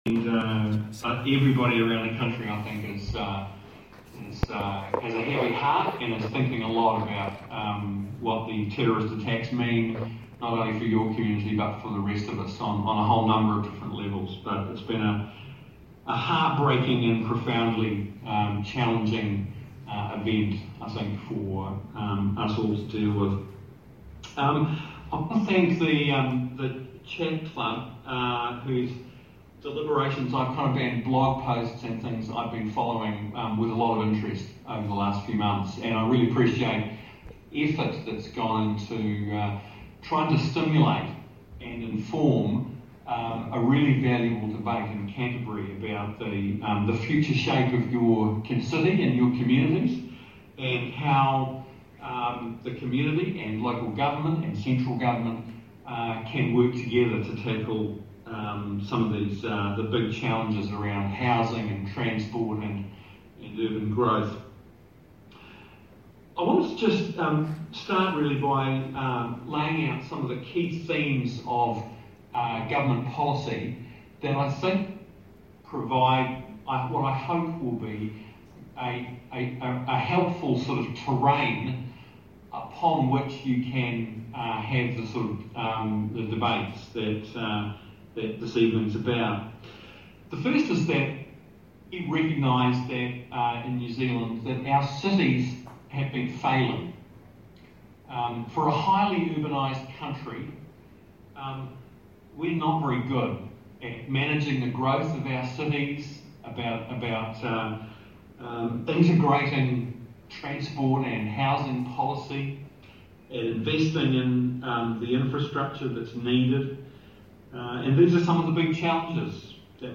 phil-twyford-speech.mp3